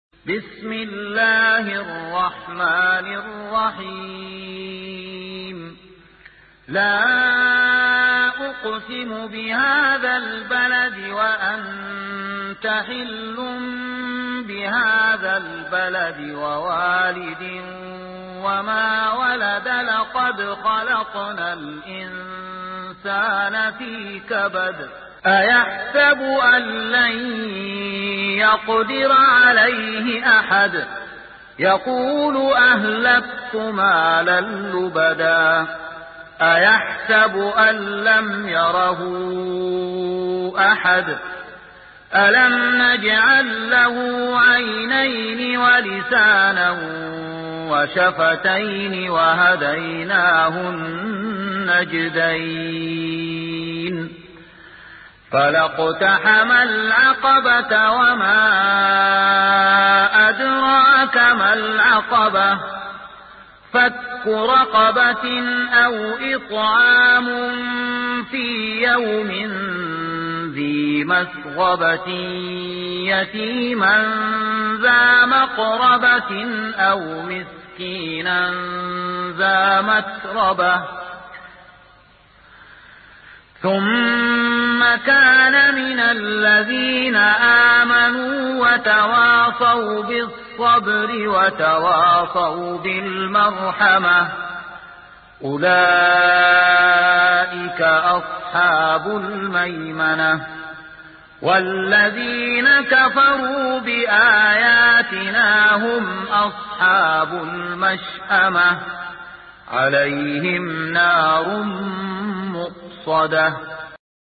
سورة البلد مكية عدد الآيات:20 مكتوبة بخط عثماني كبير واضح من المصحف الشريف مع التفسير والتلاوة بصوت مشاهير القراء من موقع القرآن الكريم إسلام أون لاين